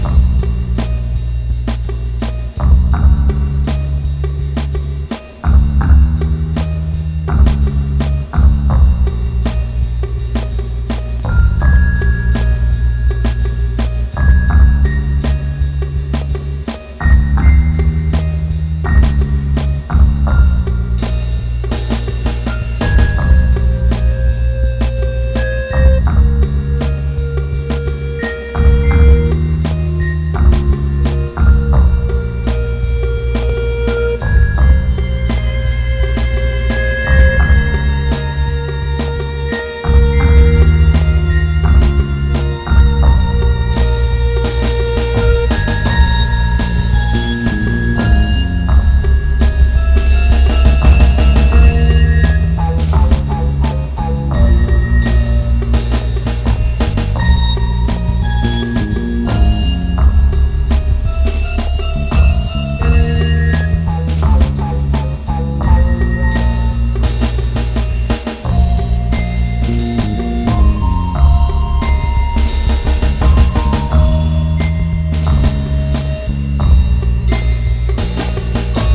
ROCK / 90''S～ / INDIE POP (UK)
心をすくいあげる哀愁メロと弾むようなリズム感でドリーミーに感動を撒き散らす